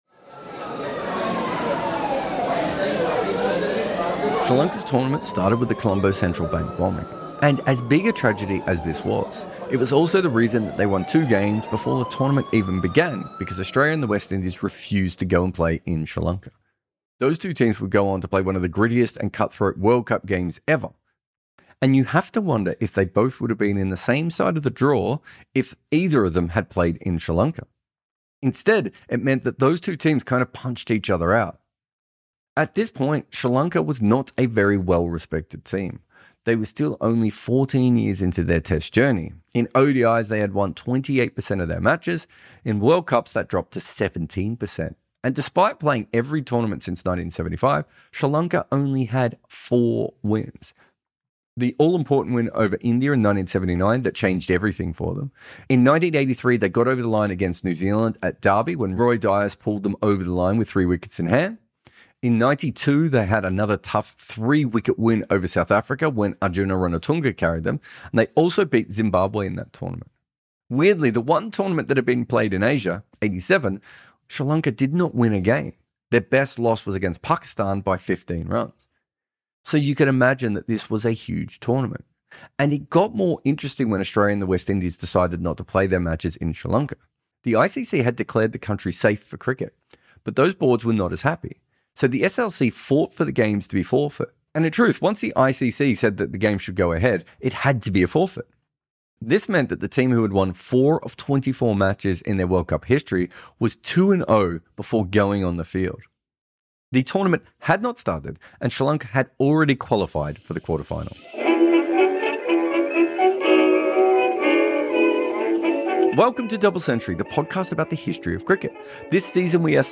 produces and narrates this series